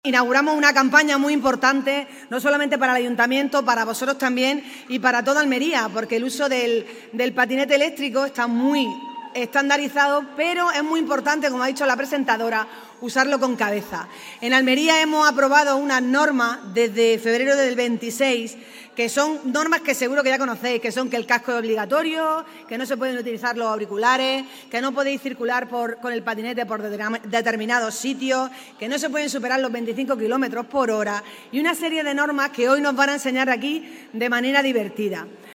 ALCALDESA-CAMPANA-SENSIBILIZACION-PATINETE-ORDENANZA.mp3